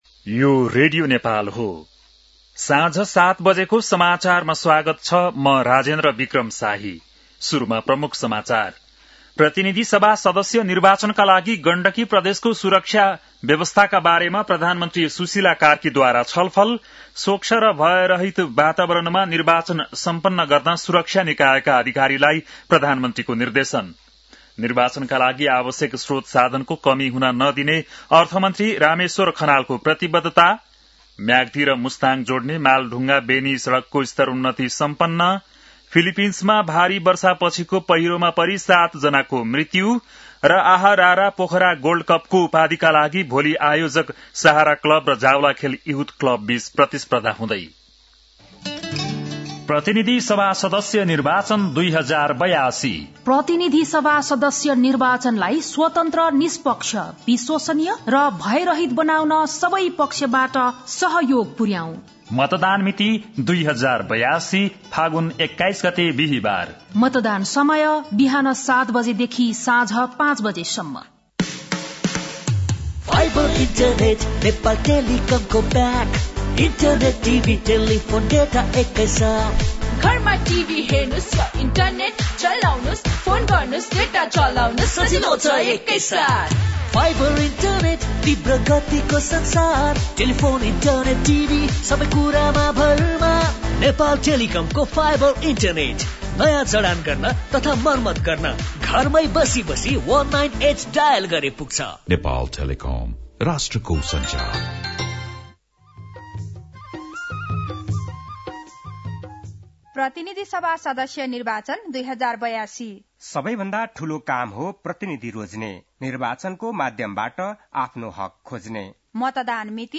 बेलुकी ७ बजेको नेपाली समाचार : ८ फागुन , २०८२
7-pm-nepali-news-11-08.mp3